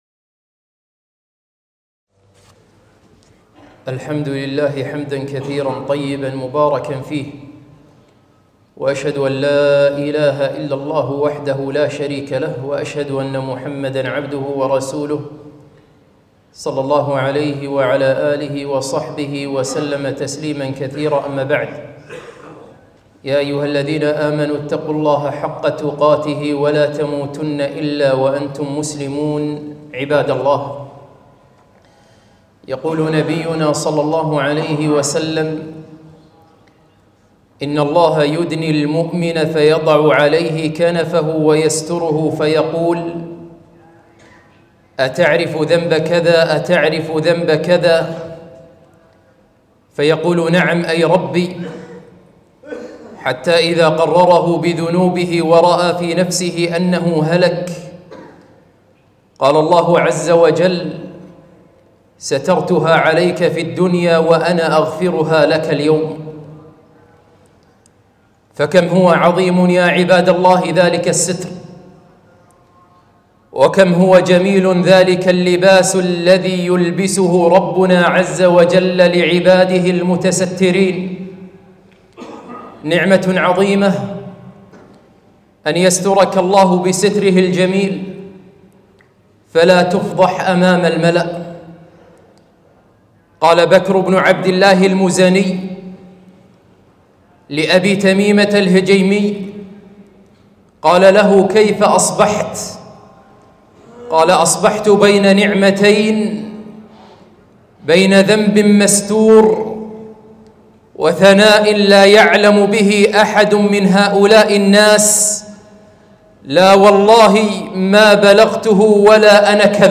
خطبة - أرجوك، لا تكشف الستر